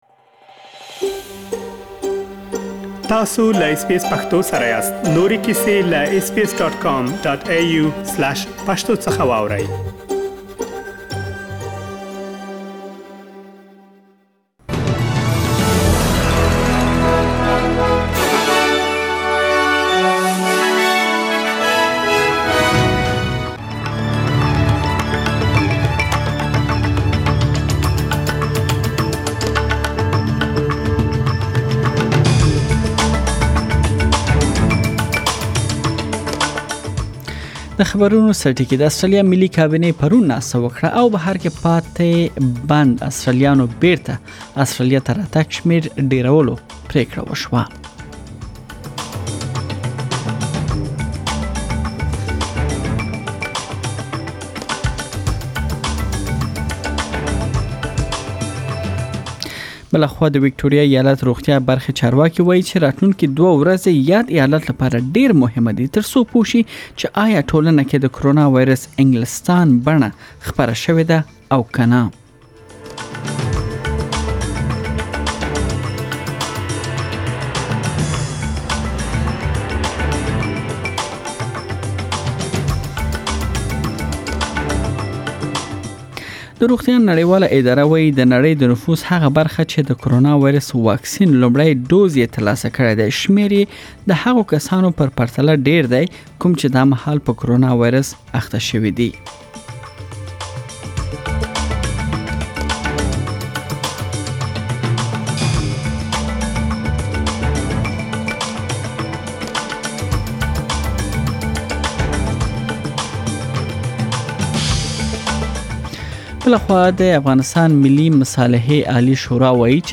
د نن ورځې مهم خبرونه